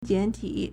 简体 (簡體) jiǎntǐ
jian3ti3.mp3